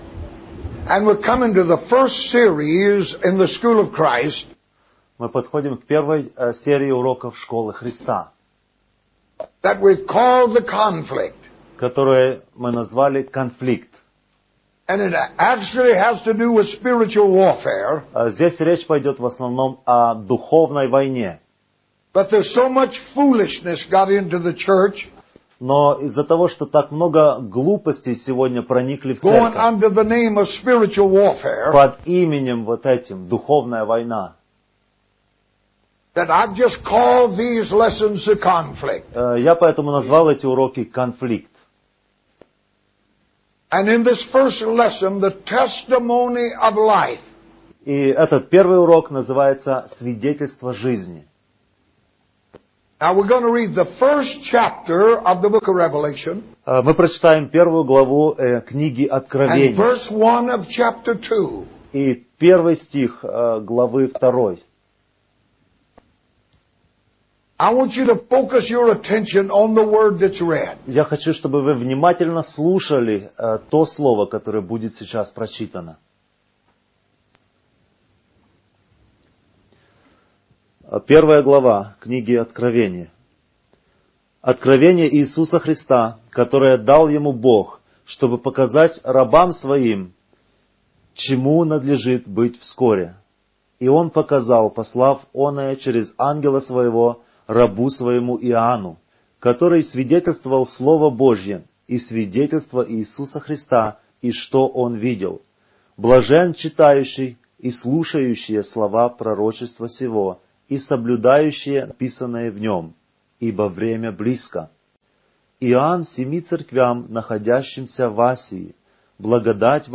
Урок № 1 - СВИДЕТЕЛЬСТВО ЖИЗНИ - Аудио